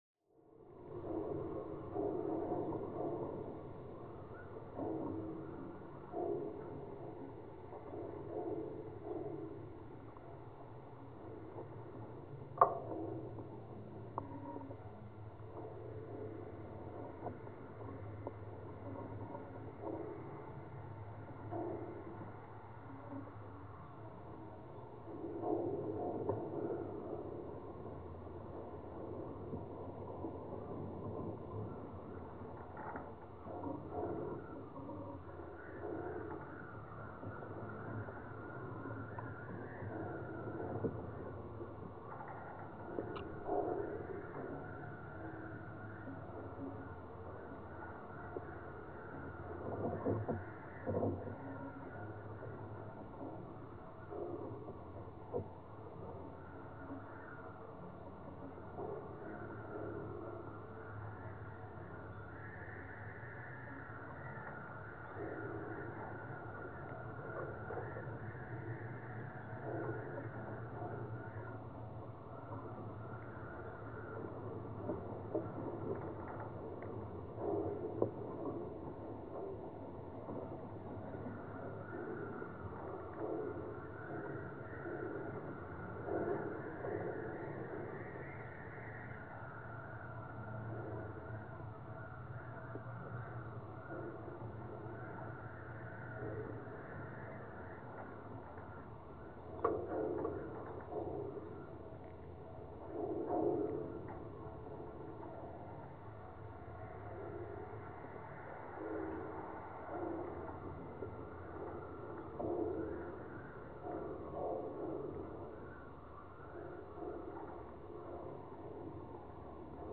I therefore recorded the vibrations and crackling of the structure using geophones.
I will also use analogue synthesis, as this is my priority at the moment.